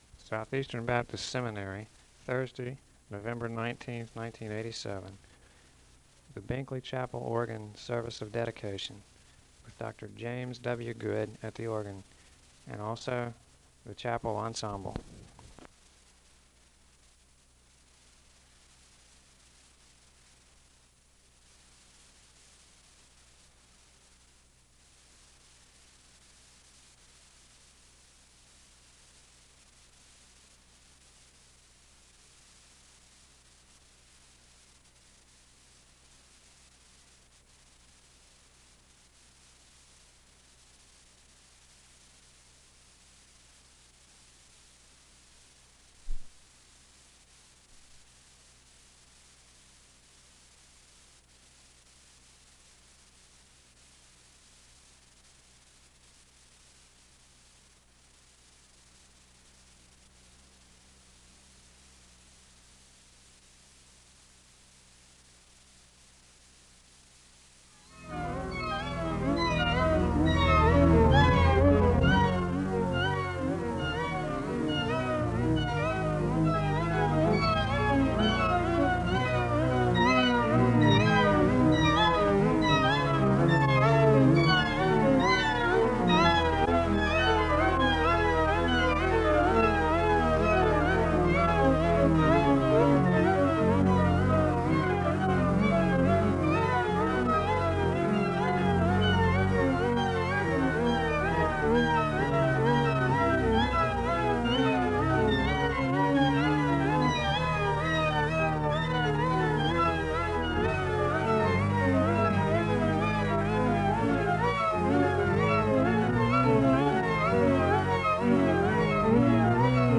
The service begins with organ music (0:00-6:25).
The choir sings a hymn of worship (7:16-9:11). There is a responsive Scripture reading from the Psalms (9:12-10:13).
The congregation joins in singing a hymn (27:53-39:00).
The service concludes with organ music (42:26-49:20).
Organ and piano music Dedication services